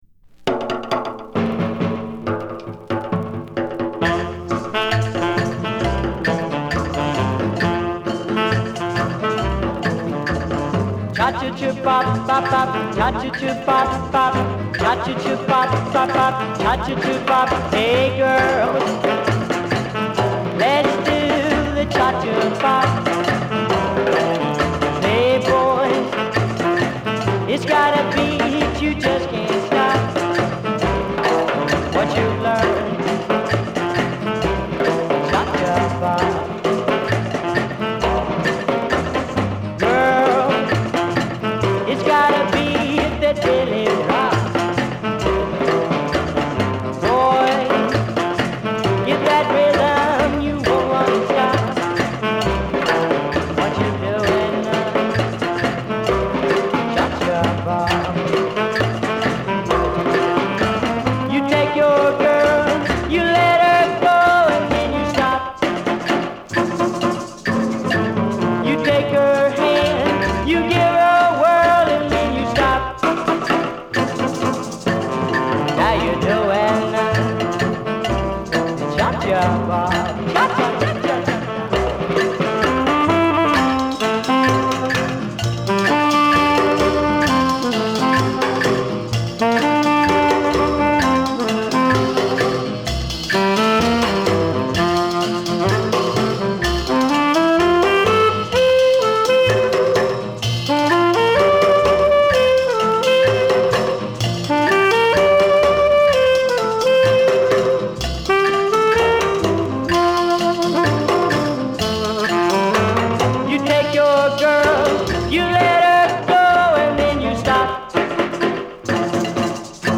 プライヴェート盤らしいプリミティヴな音質がグレート。